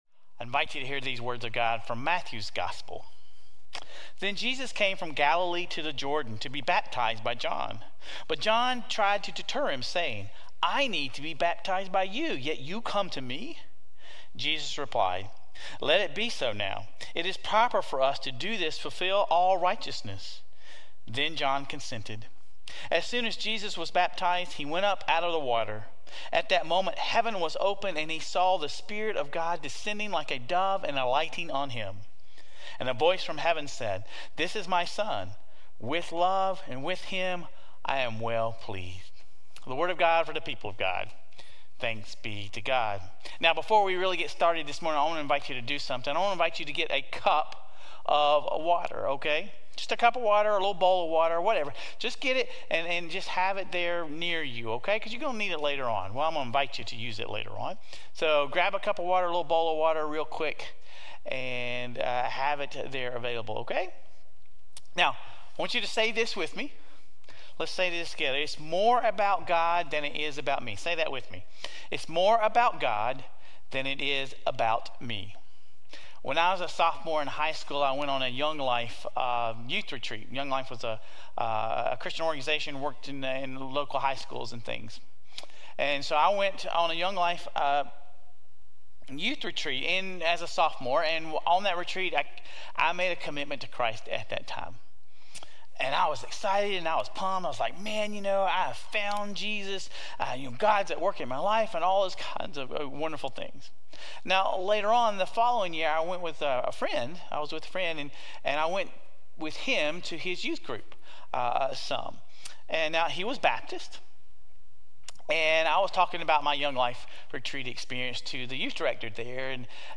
Sermon Reflections: How does the sermon illustrate the concept of a covenant between God and people?